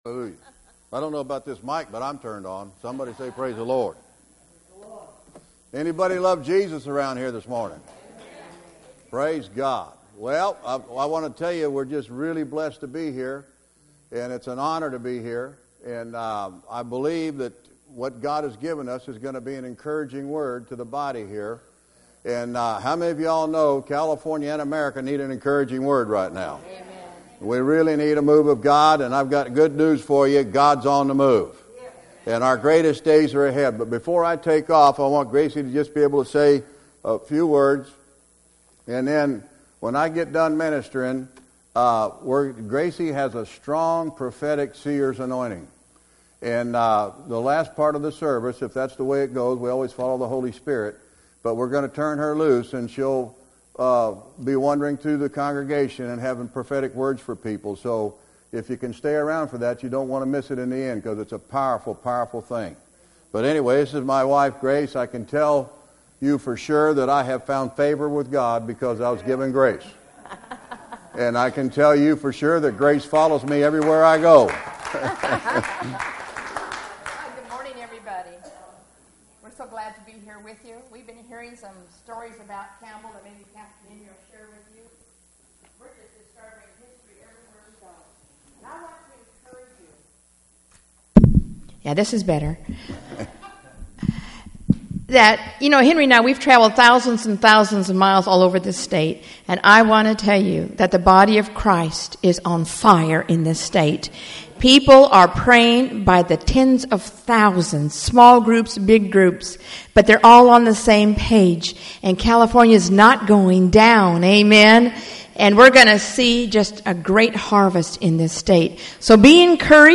Special Sermons